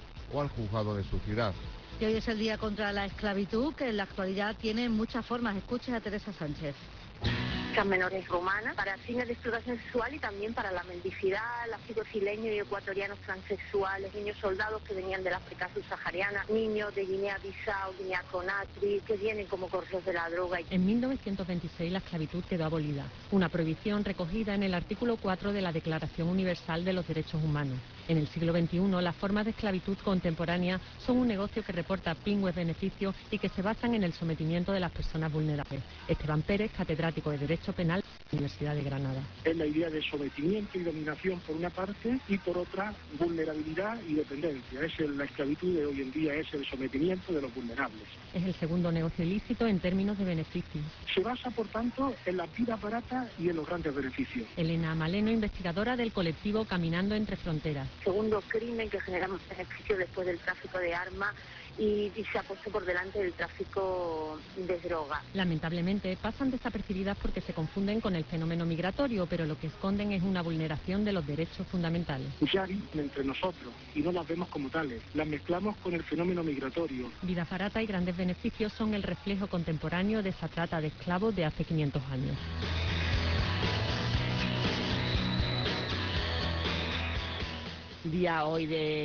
AUDIO – Canal Sur Radio – HORA SUR MEDIODÍA: DECLARACIONES